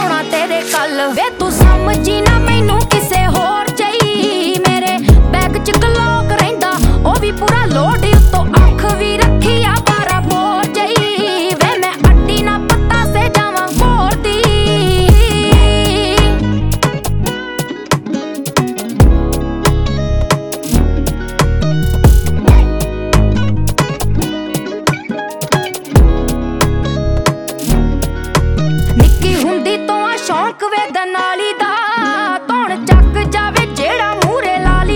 Punjabi Indian Regional Indian